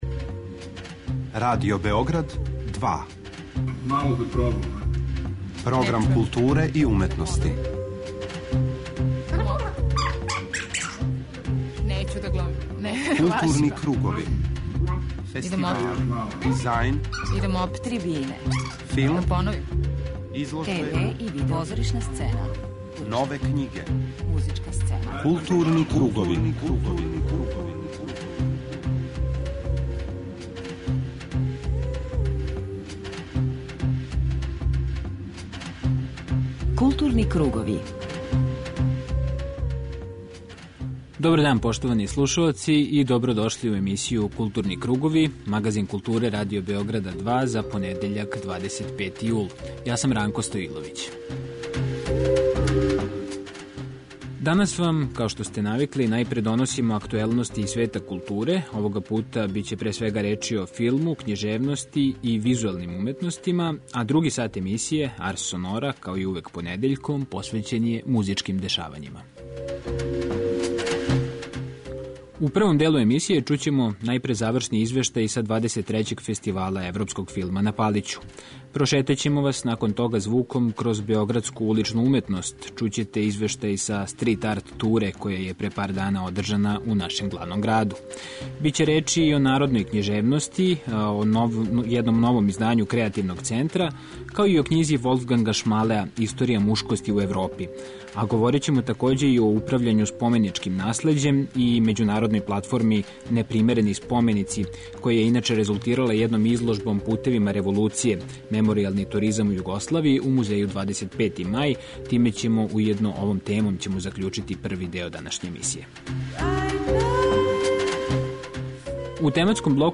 У првом делу емисије прошетаћемо вас звуком кроз београдску уличну уметност - чућете извештај са Street art туре.